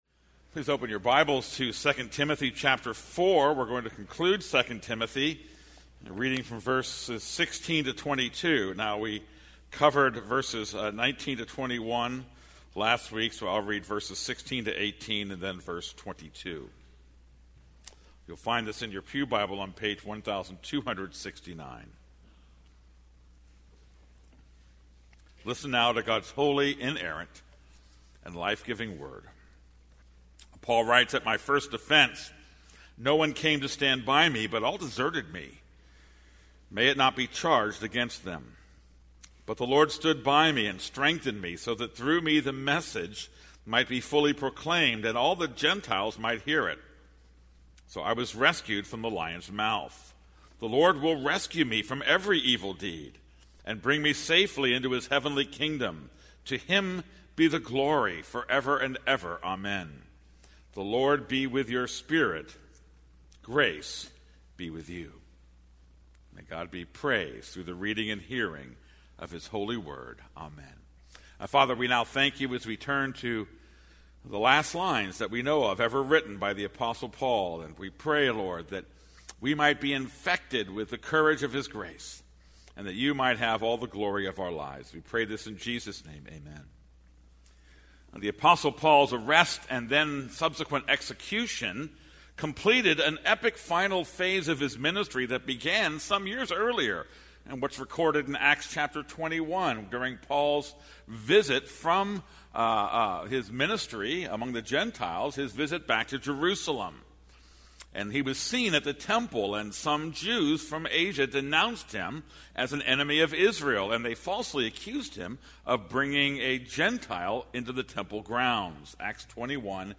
This is a sermon on 2 Timothy 4:16-22.